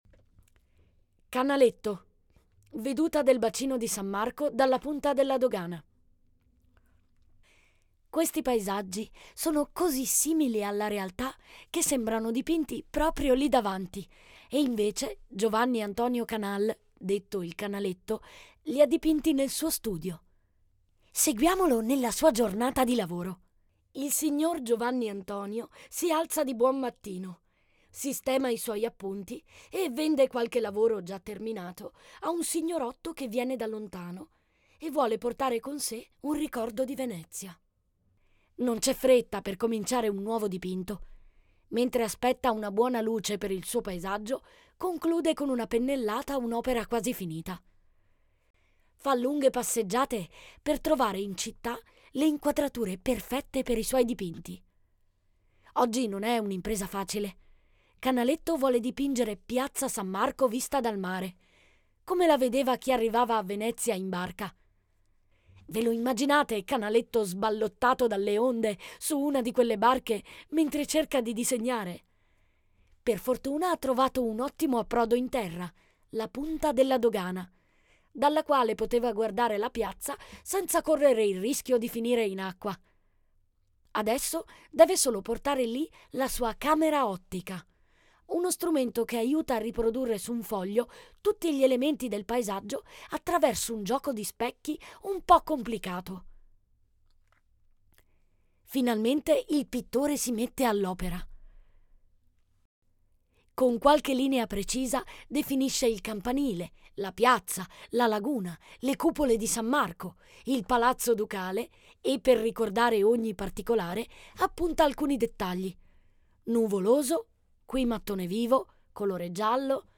AI Audio